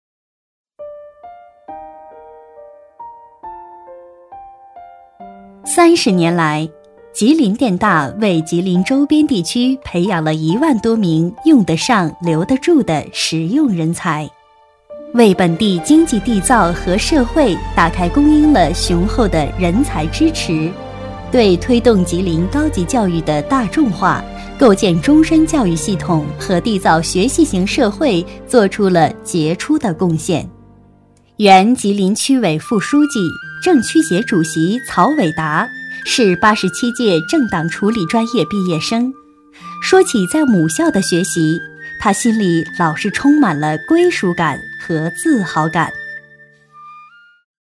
女31-学校专题（稳重 年轻）
女31-学校专题（稳重 年轻）.mp3